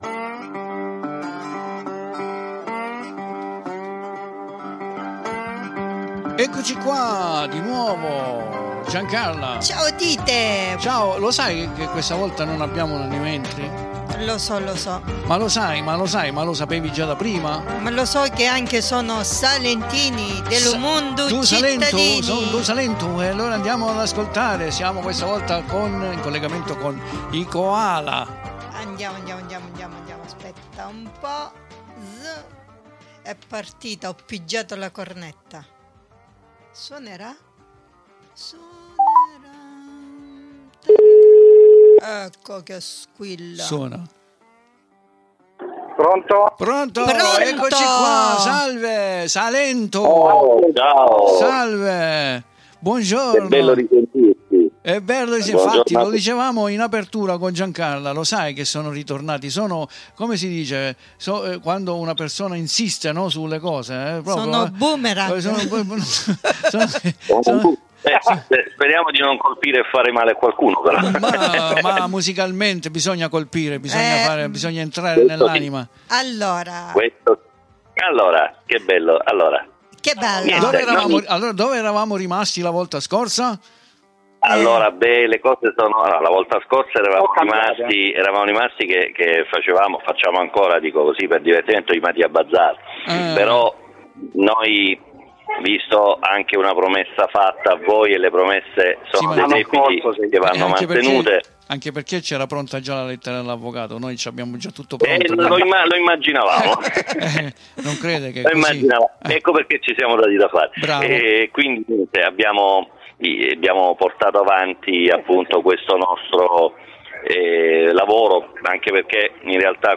94680_INTERVISTA_Koala.mp3